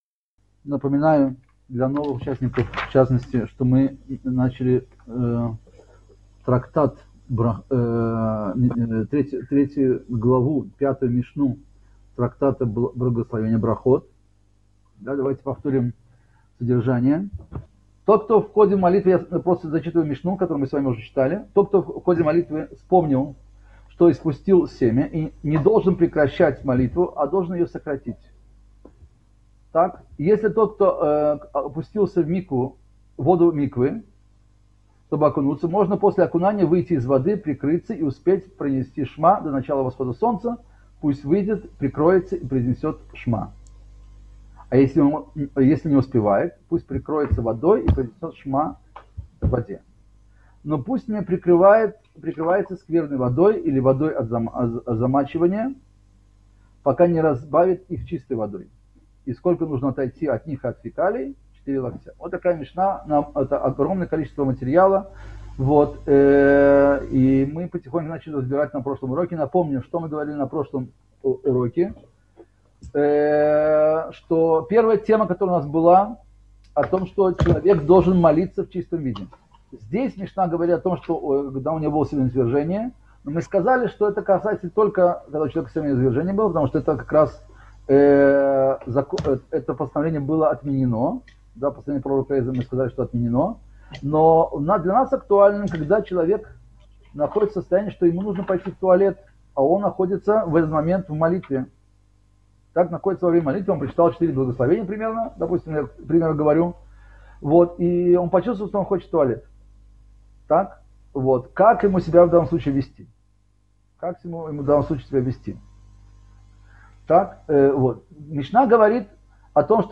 Цикл уроков по изучению мишны Брахот